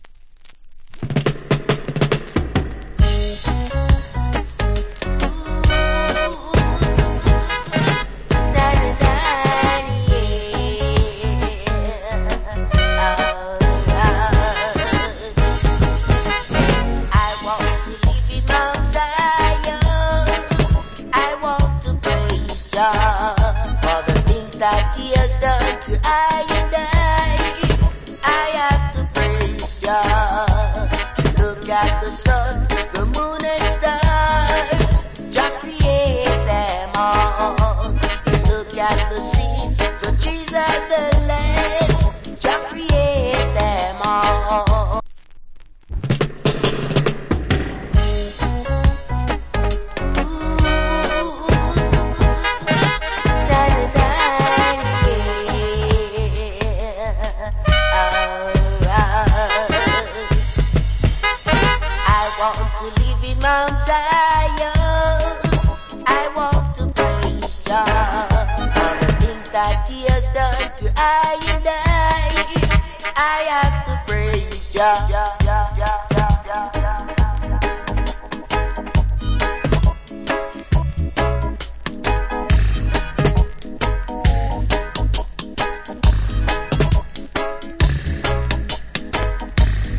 Killer Roots Rock Vocal.